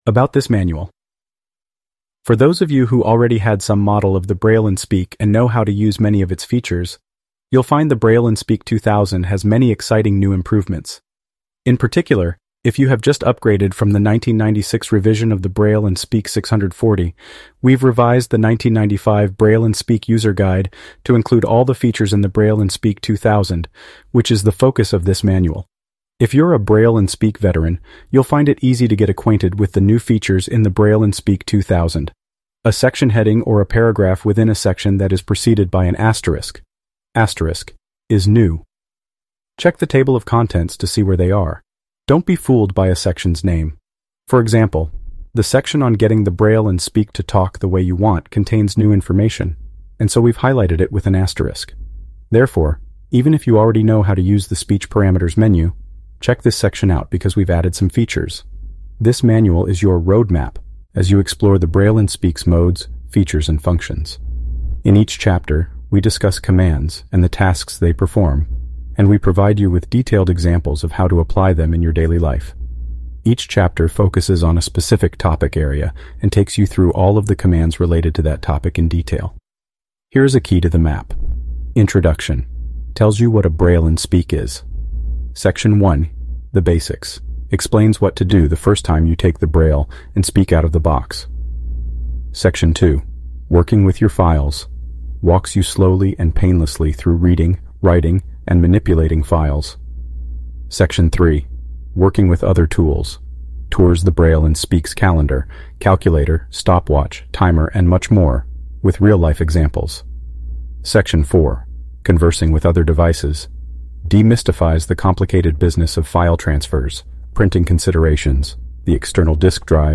In July of 2019, NOAA broke. It seems that for some reason, they briefly lost their TTS license, and as a result, demo messages were all over the weather forecasts in several states for a few hours.